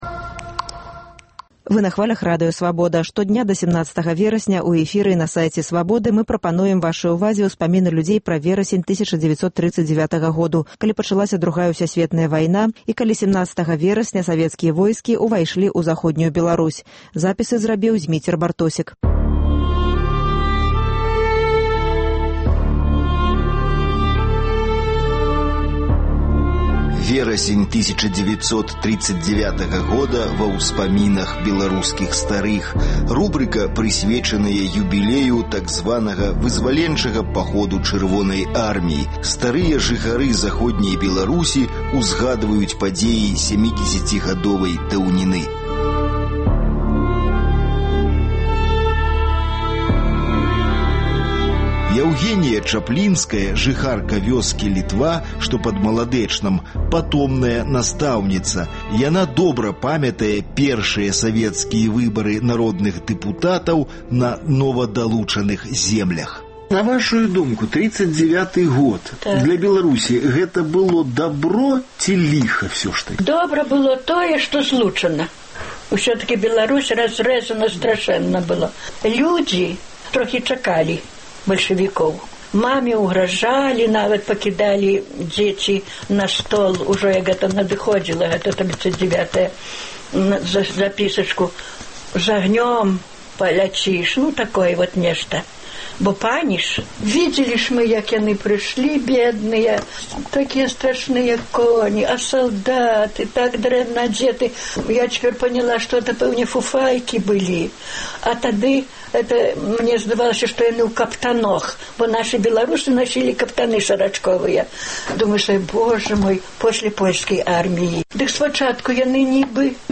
Штодня да 17 верасьня ў эфіры і на сайце "Свабоды" мы прапануем вашай увазе успаміны людзей пра верасень 1939 году, калі пачалася Другая ўсясьветная вайна і калі 17 верасьня савецкія войскі ўвайшлі ў Заходнюю Беларусь.